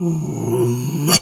pgs/Assets/Audio/Animal_Impersonations/bear_pain_hurt_groan_03.wav at master
bear_pain_hurt_groan_03.wav